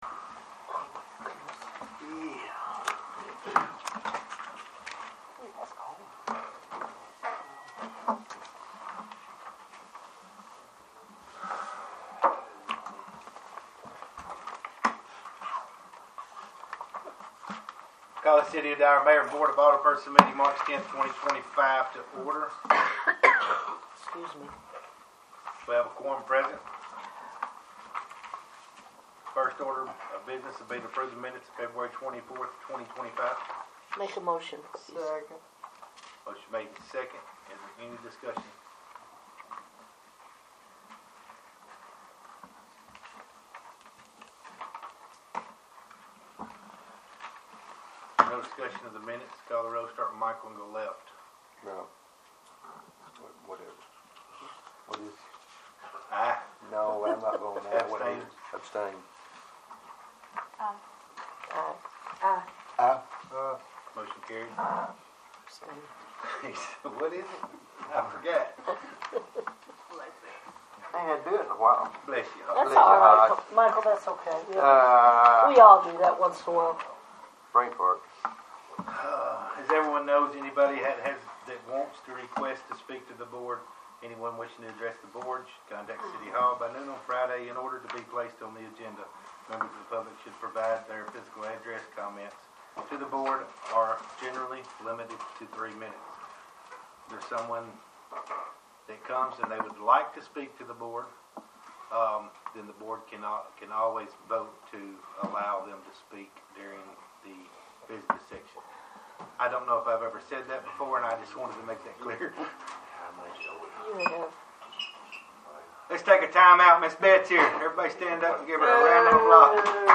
3-10-25 Regular Meeting
3-10-25-Regular-Meeting.mp3